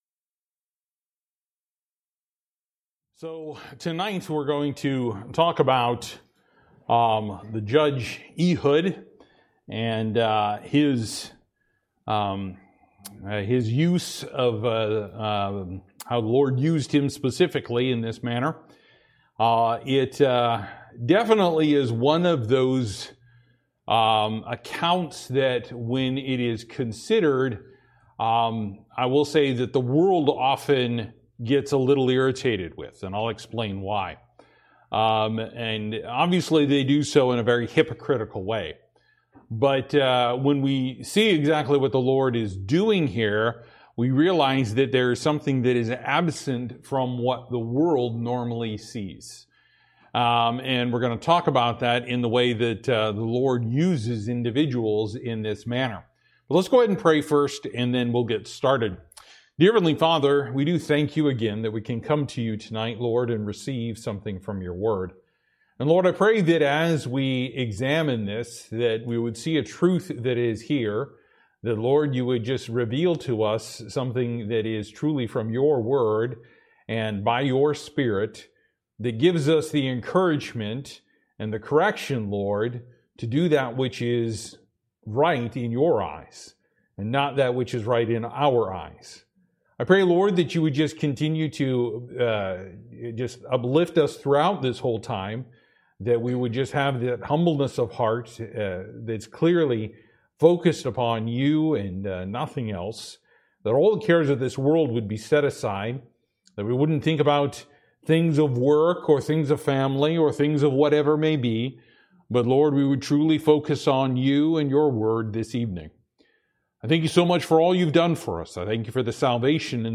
“Judges” – Lesson 4